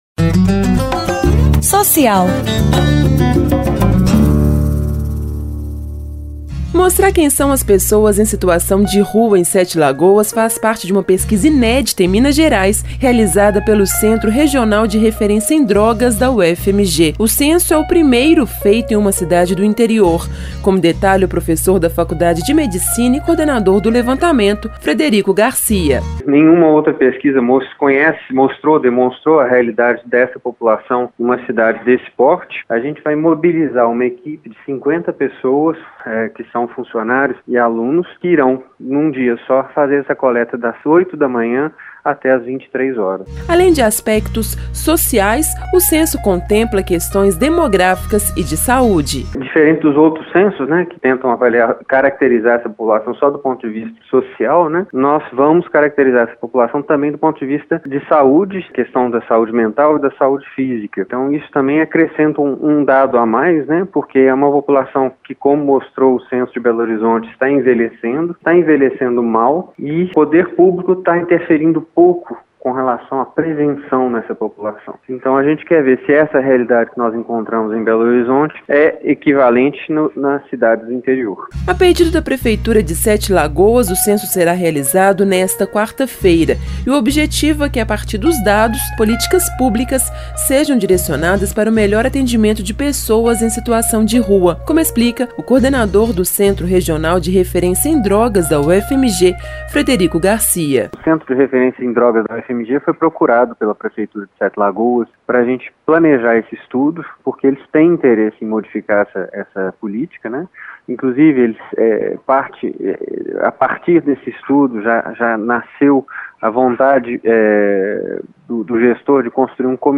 Reportagens da produção